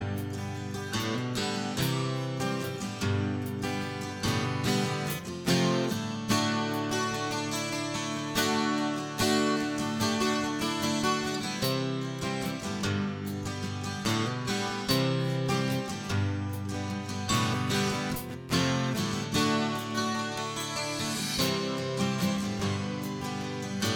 Minus Electric And Solo Rock 4:57 Buy £1.50